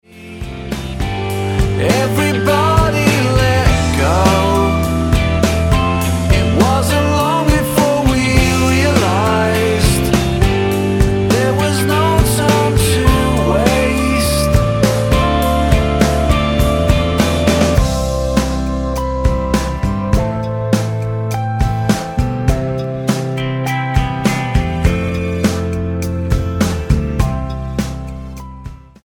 STYLE: Rock
blending a gospel choir into the coda of the song